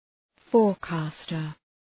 Προφορά
{‘fɔ:r,kæstər}
forecaster.mp3